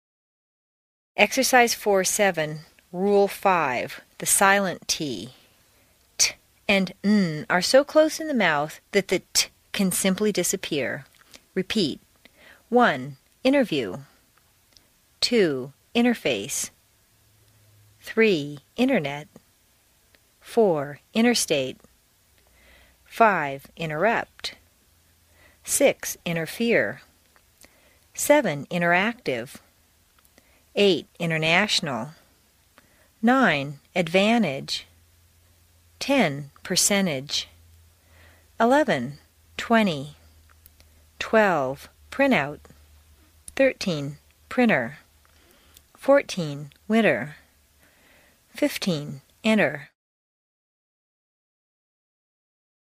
在线英语听力室美式英语正音训练第60期:练习4(7)的听力文件下载,详细解析美式语音语调，讲解美式发音的阶梯性语调训练方法，全方位了解美式发音的技巧与方法，练就一口纯正的美式发音！